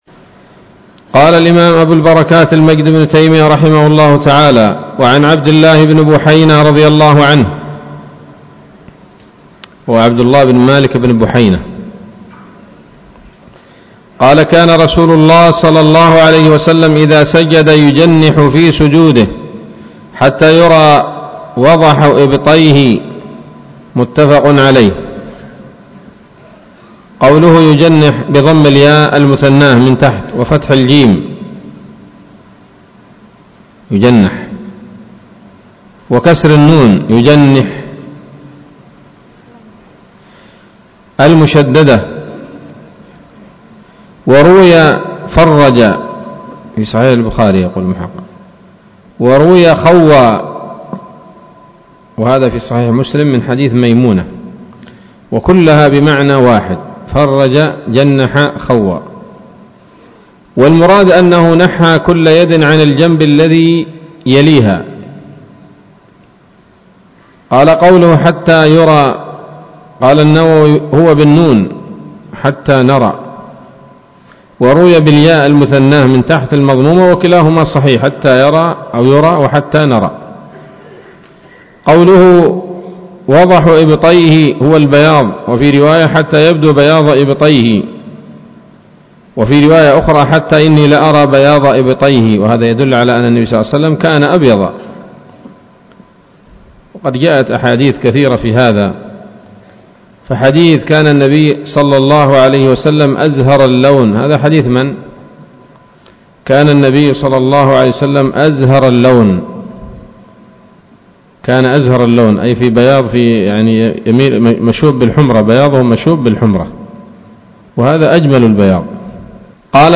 الدرس الثاني والستون من أبواب صفة الصلاة من نيل الأوطار